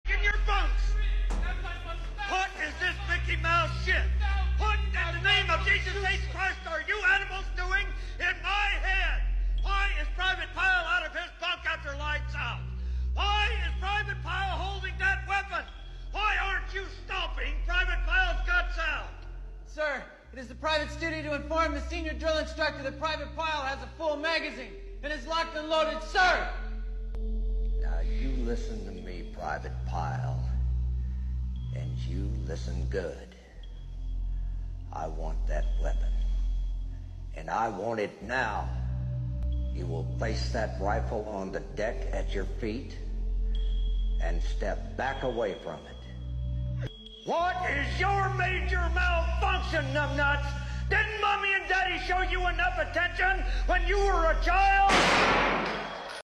Full Metal Jacket (1987) Iconic Scene Between Gunnery Sergeant and Private Pyle.